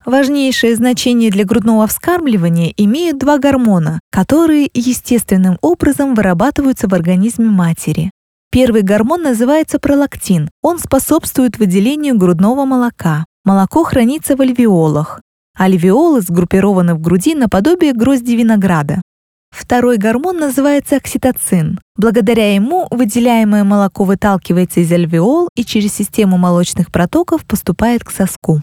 Russische Synchronsprecherin
Unternehmensvideos
Ich arbeite mit hochwertiger professioneller Ausrüstung, um den allerbesten Klang zu gewährleisten.